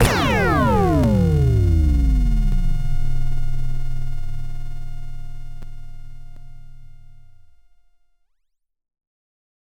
TM88 Laser Shot.wav